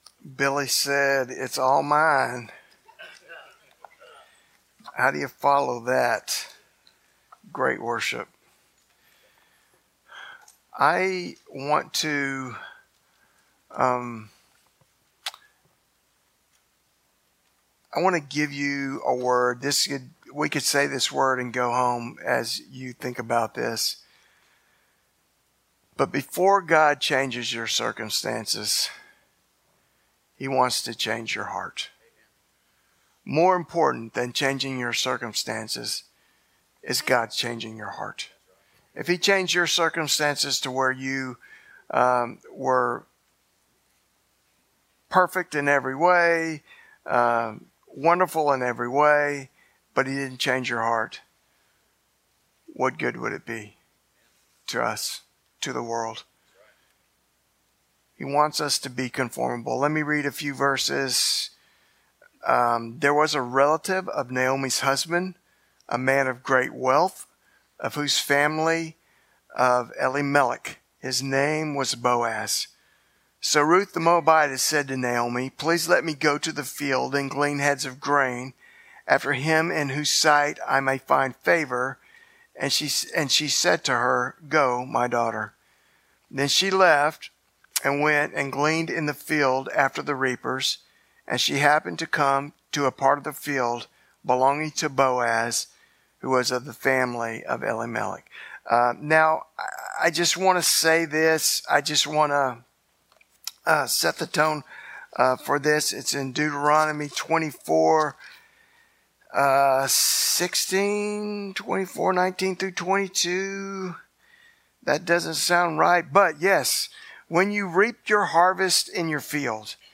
Sermons 2025